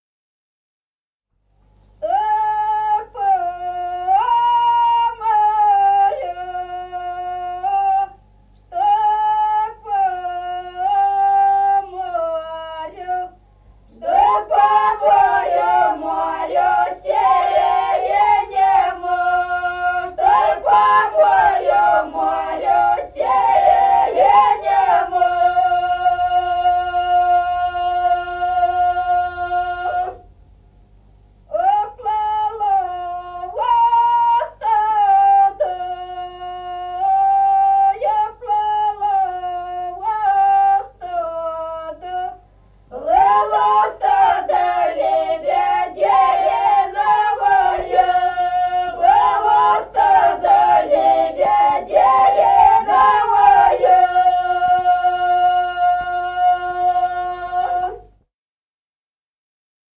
Народные песни Стародубского района «Чтой по морю», карагодная.
1953 г., с. Остроглядово.